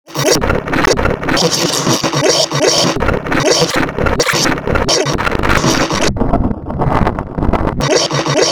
Download Goofy Ahh Scream sound effect for free.
Goofy Ahh Scream